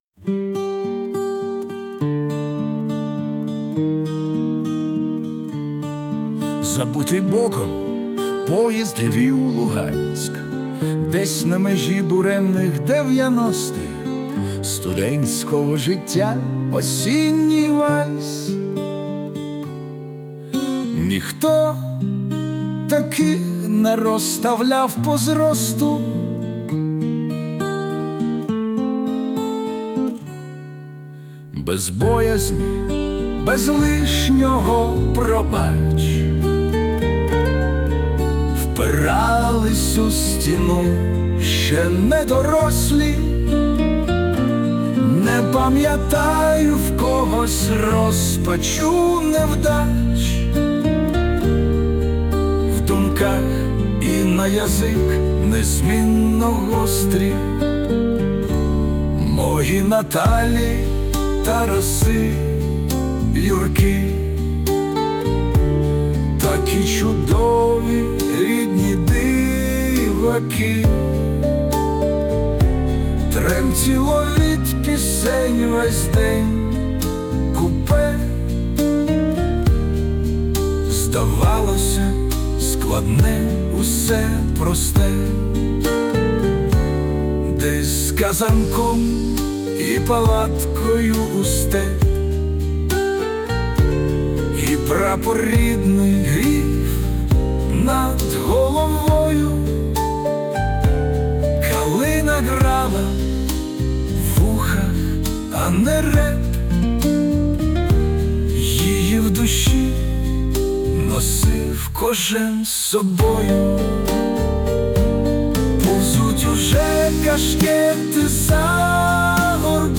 Варіант пісні
Музичний супровід з допомогою ШІ